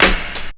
INSTCLAP11-L.wav